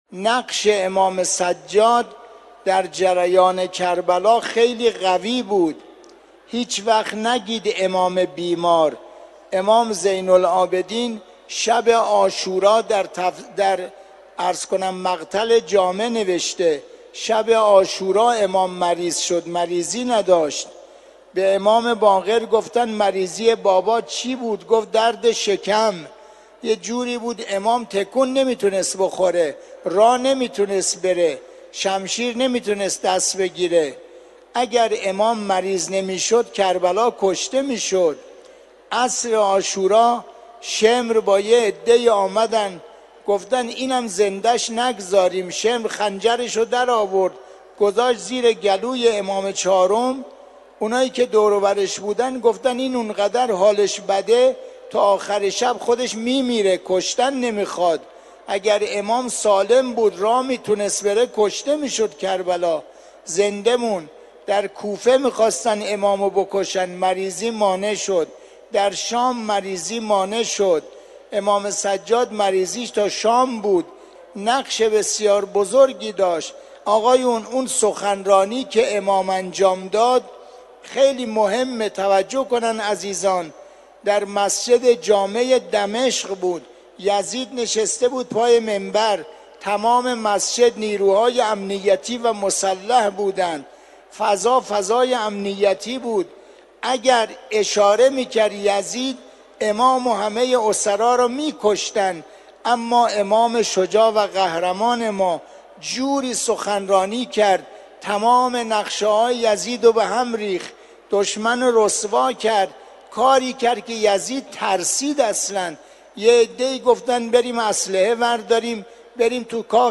سخنرانی
در حرم مطهر رضوی